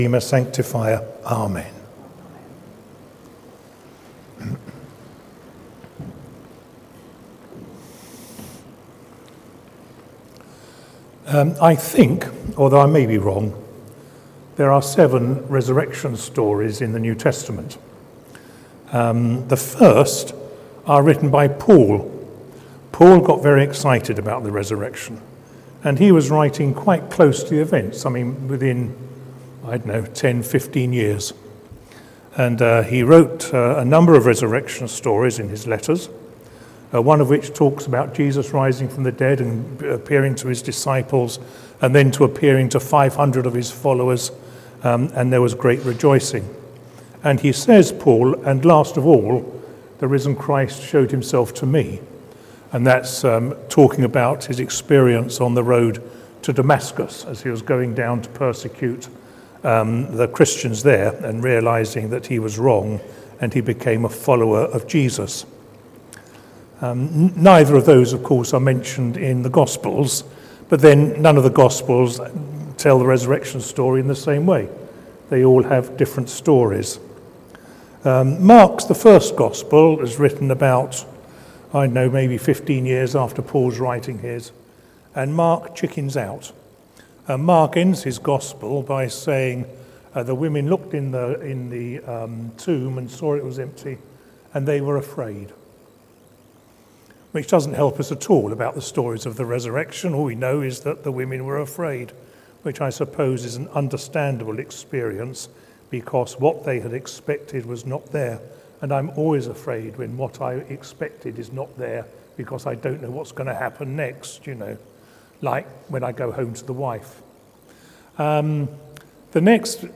Sermon: John and the Resurrection | St Paul + St Stephen Gloucester